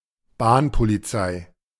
Bahnpolizei (German pronunciation: [ˈbaːnpoliˌtsaɪ]
De-Bahnpolizei.ogg.mp3